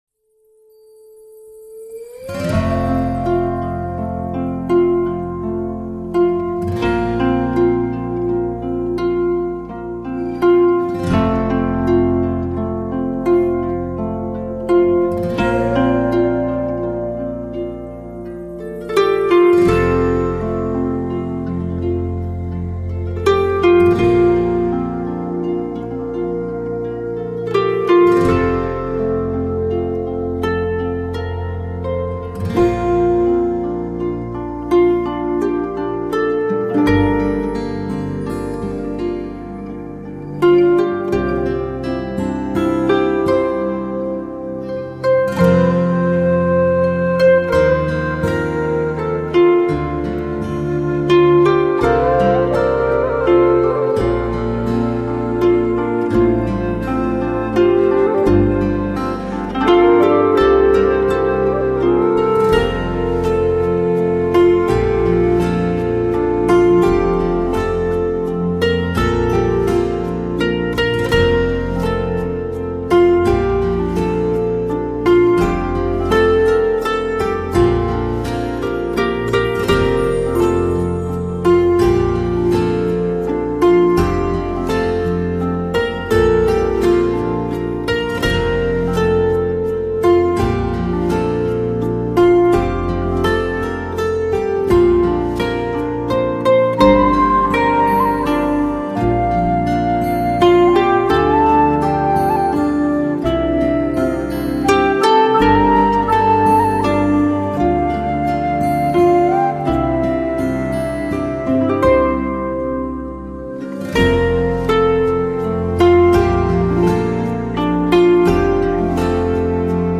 她的音乐较之一般的凯尔特更有一种浓浓的暖意，